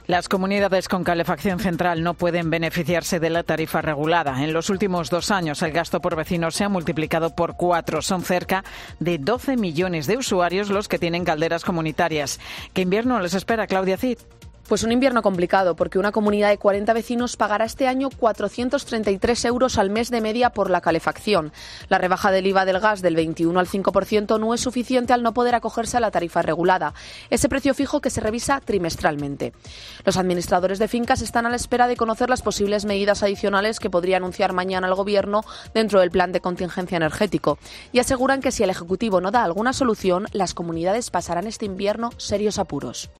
Una comunidad de vecinos de Logroño pasará el invierno sin calefacción. Crónica